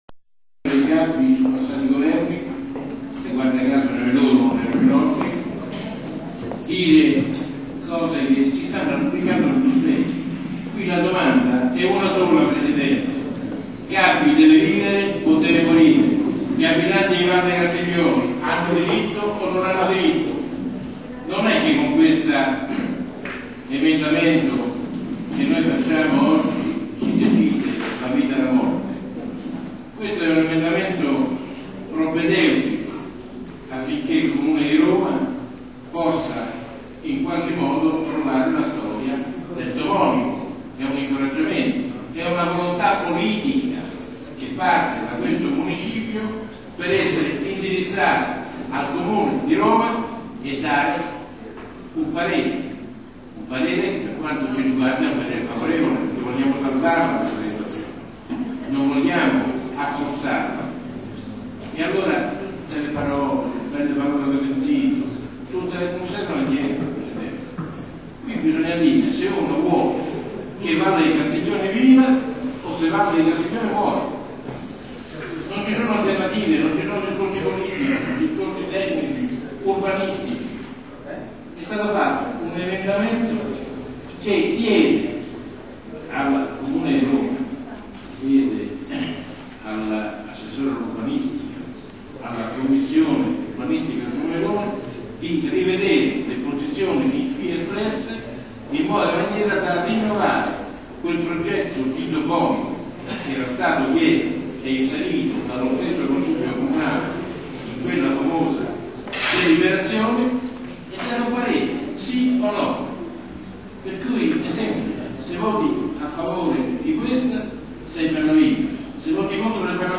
Consiglio Municipale del 31 ottobre 2003 per la proposta di osservazioni al Piano.
Archivio sonoro degli interventi.
Consigliere del Municipio 8°